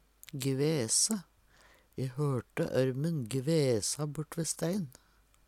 DIALEKTORD PÅ NORMERT NORSK gvese kvese, frese Infinitiv Presens Preteritum Perfektum gvese gvesar gvesa gvesa Eksempel på bruk E hørte ørmen gvesa bortve stein.